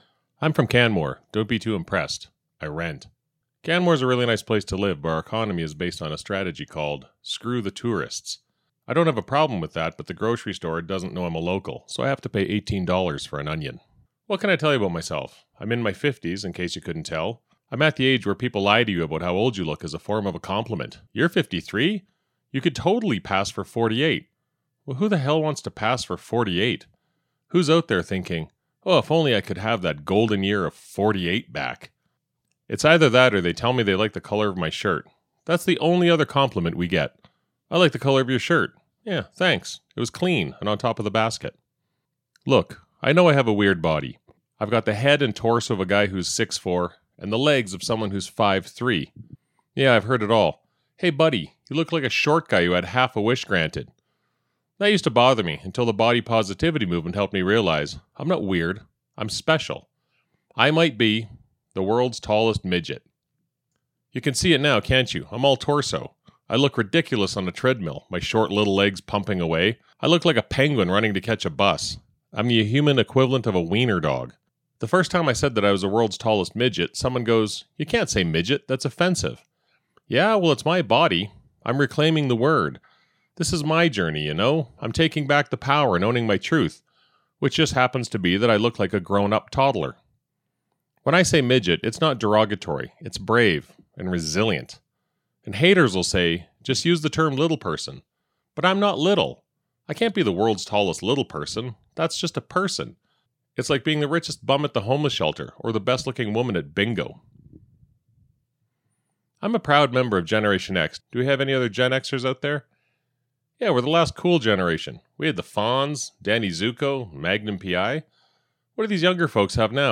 July Ten Minutes Practice
This is not much different than they previous practice recordings.